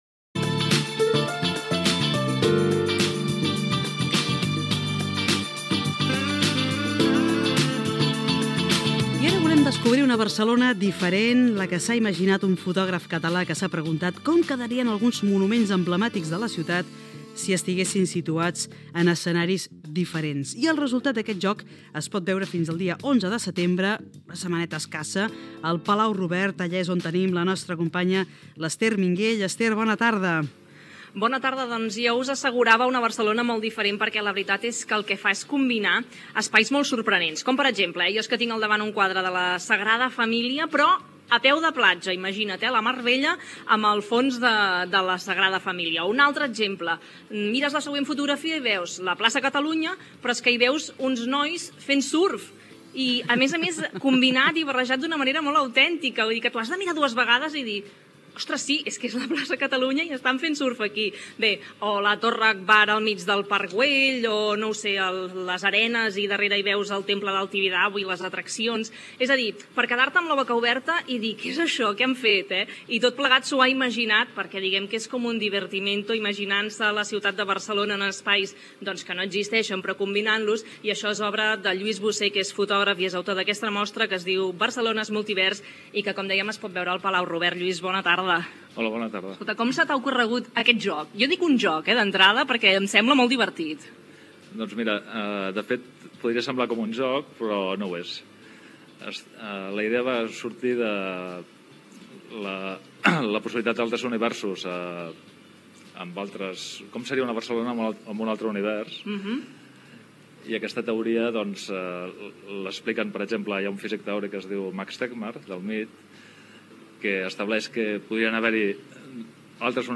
Entrevista
Gènere radiofònic Entreteniment